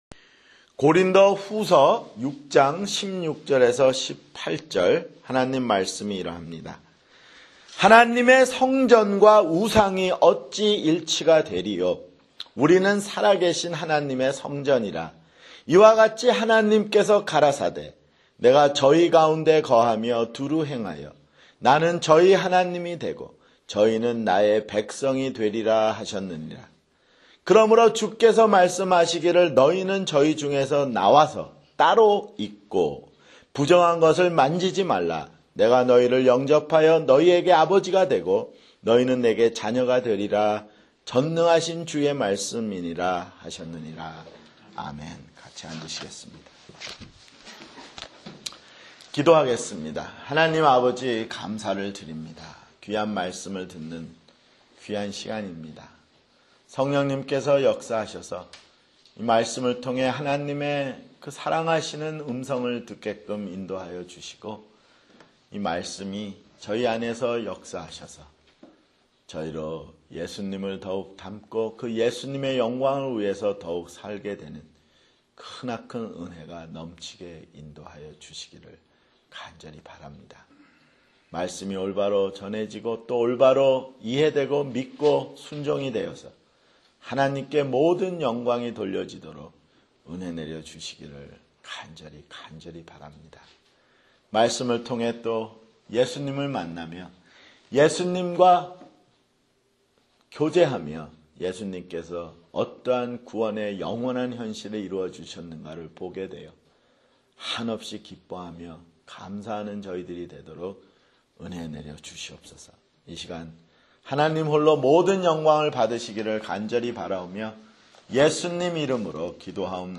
[주일설교] 고린도후서 (37)